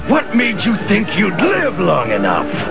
From the Spider-Man animated series.